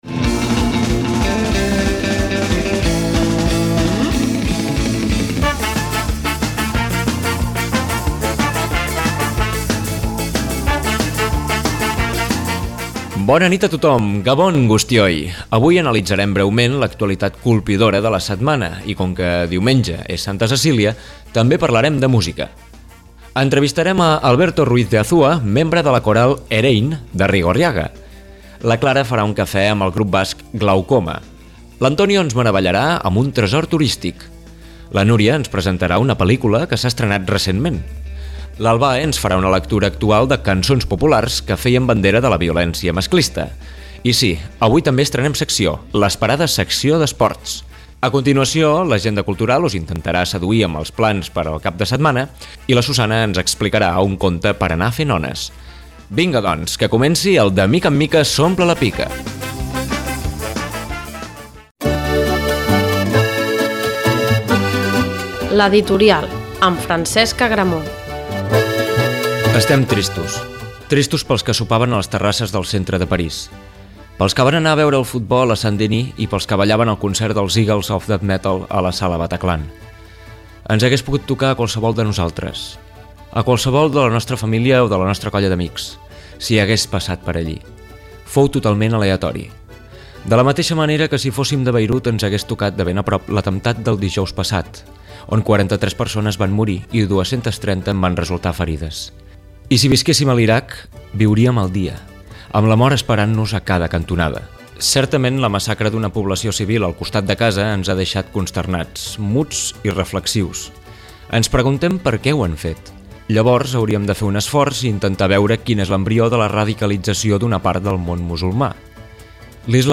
entrevistem al grup tolosarra Glaukoma.